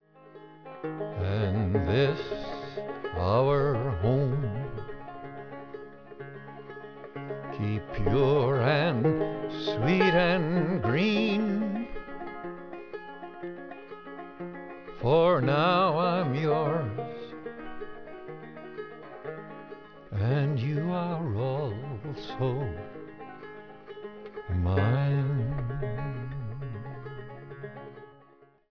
voice, banjo